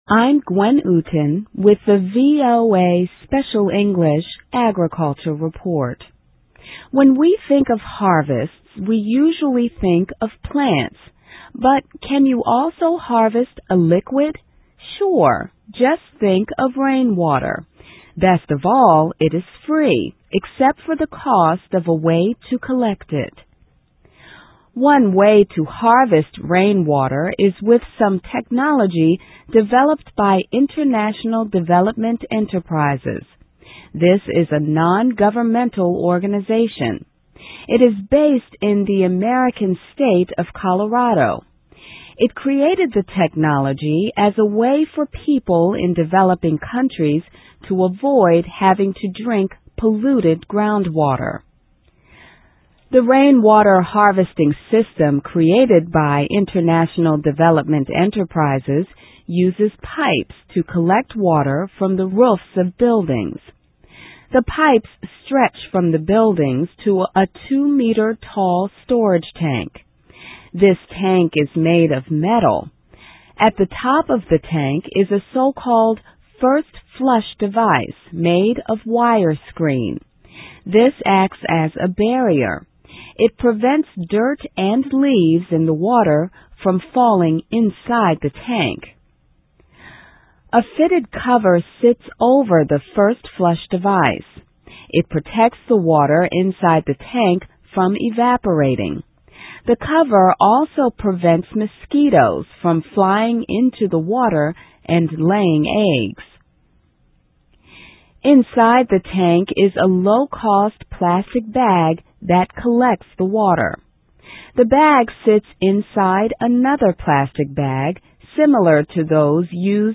A Liquid Crop: Looking for a Way to Harvest Rainwater? (VOA Special English 2005-04-19)